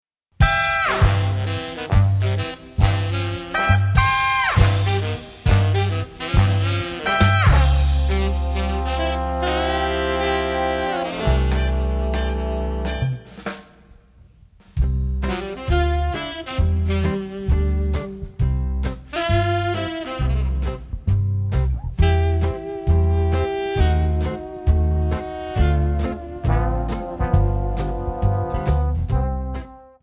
Backing track Karaoke
Pop, Oldies, Jazz/Big Band, 1950s